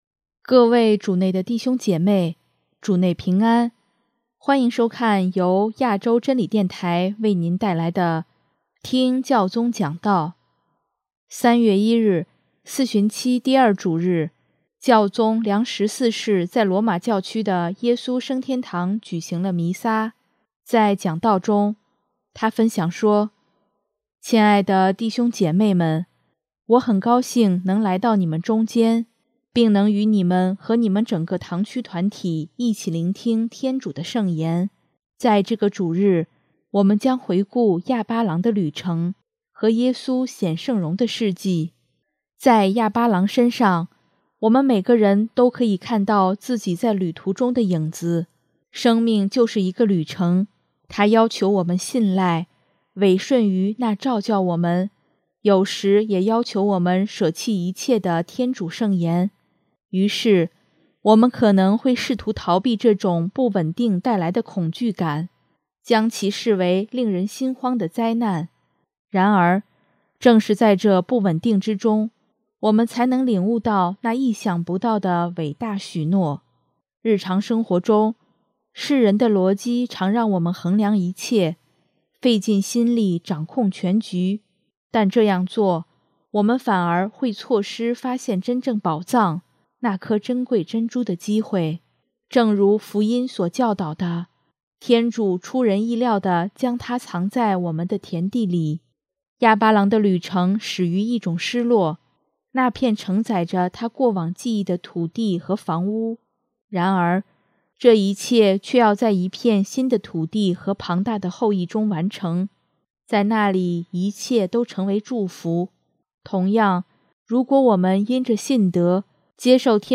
3月1日，四旬期第二主日，教宗良十四世在罗马教区的耶稣升天堂举行了弥撒，在讲道中，他分享说：